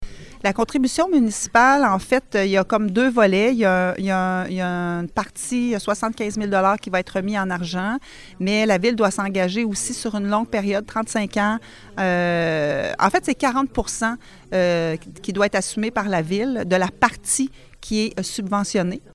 Toujours, lors de la séance du conseil, la mairesse a chiffré la part de la ville au Programme d’habitation abordable Québec.